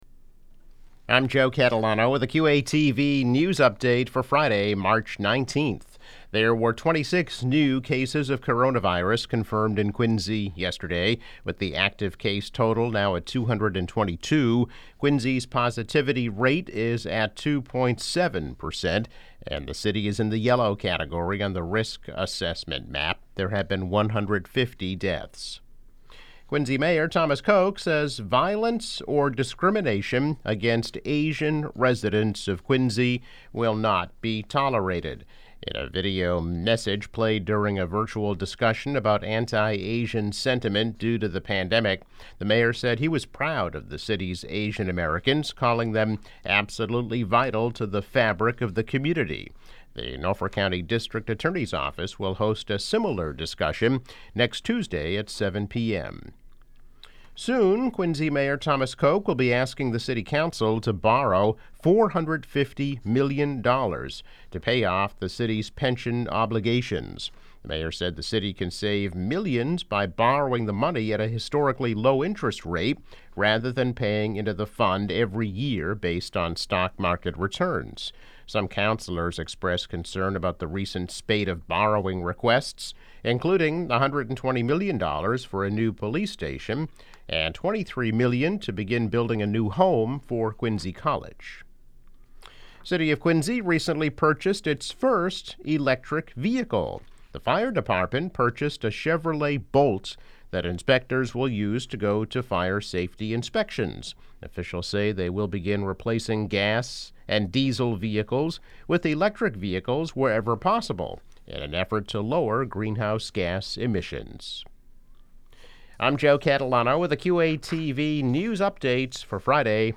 News Update - March 19, 2021